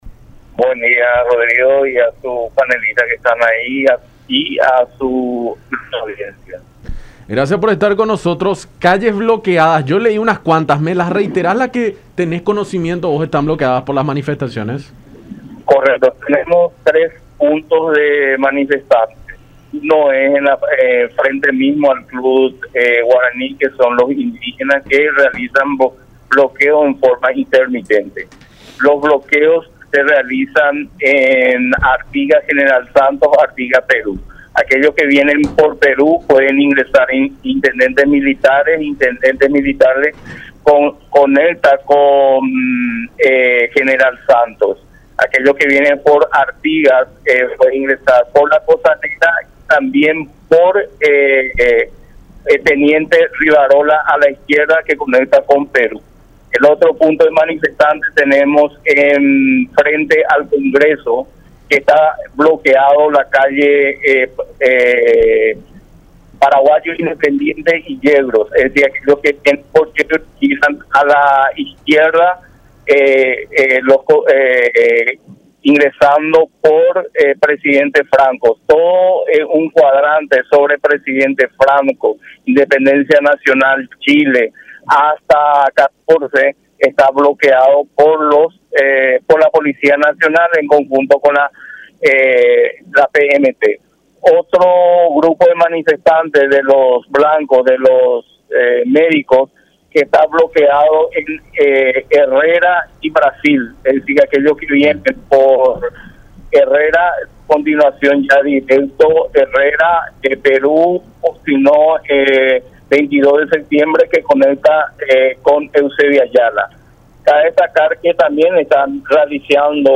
en conversación con Enfoque 800 por La Unión.